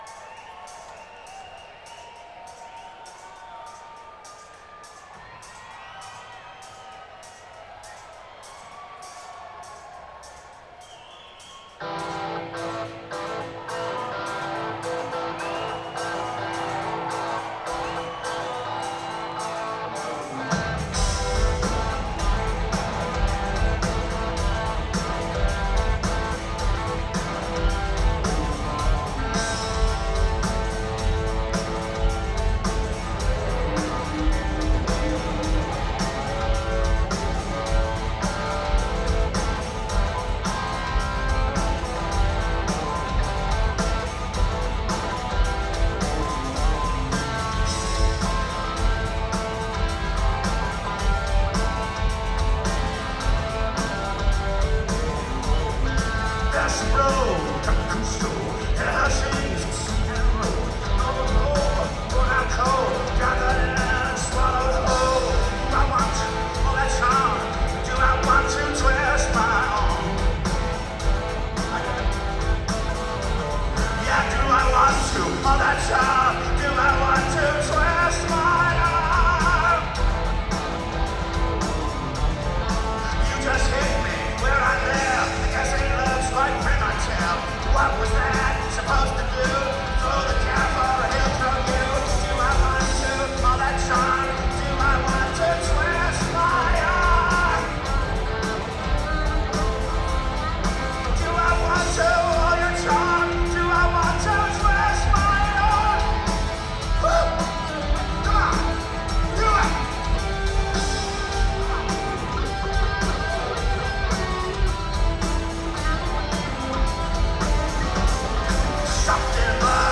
Live In 2013